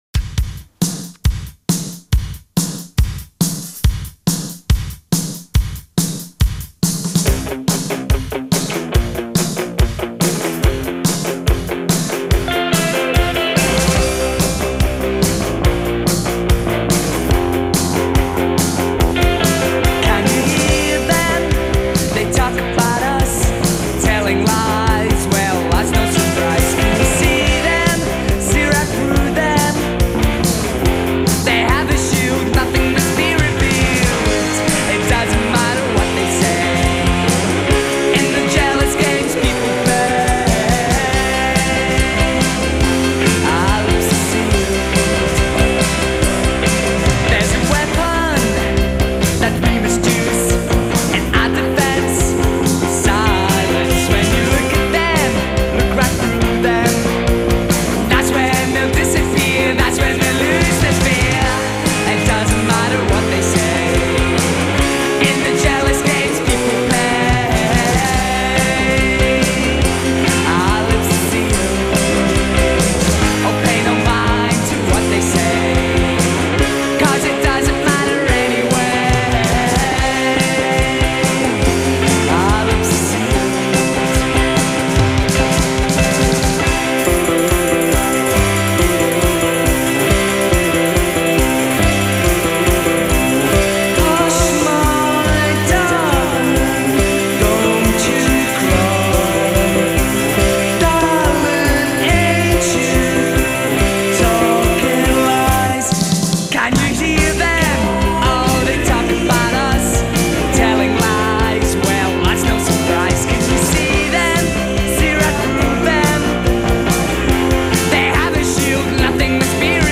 glimpse of New-Psych to come.